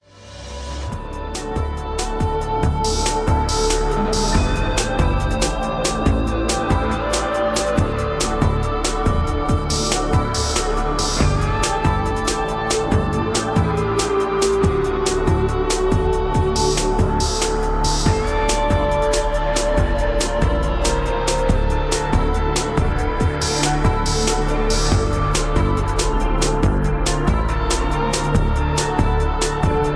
Background music suitable for TV/Film use.